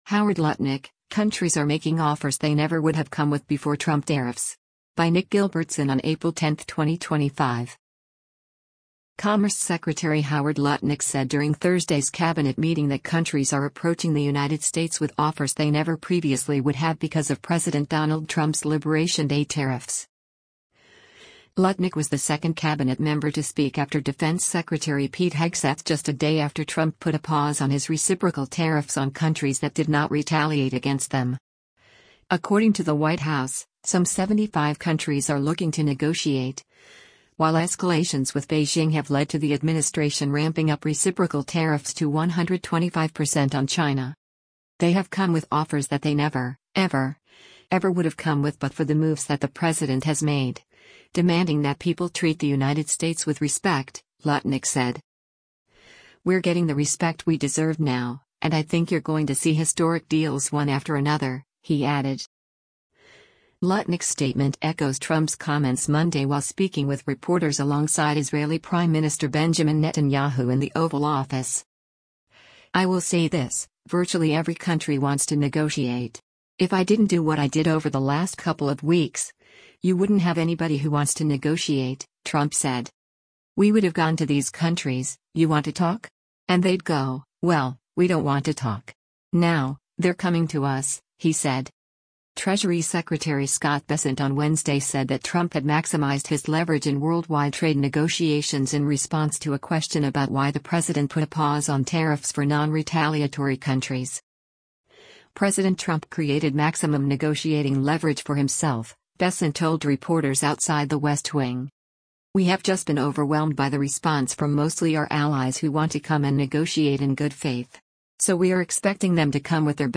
Commerce Secretary Howard Lutnick said during Thursday’s Cabinet meeting that countries are approaching the United States with offers they never previously would have because of President Donald Trump’s “Liberation Day” tariffs.